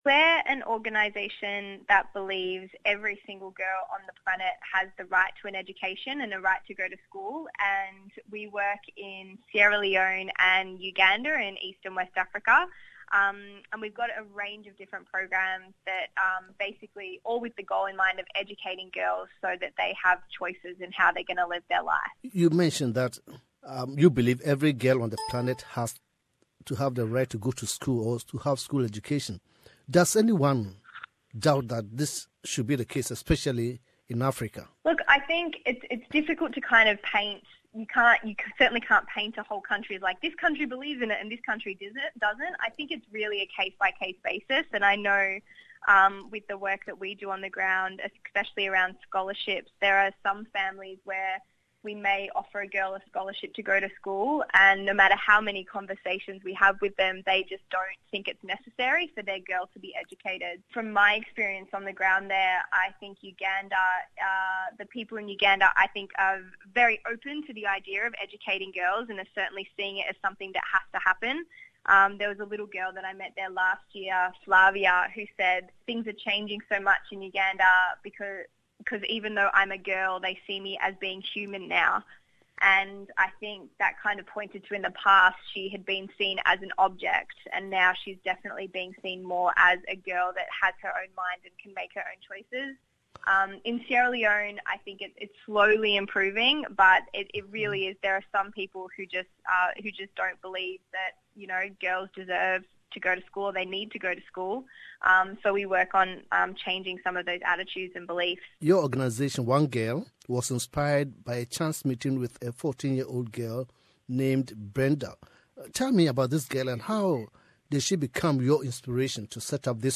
decided to replay the conversation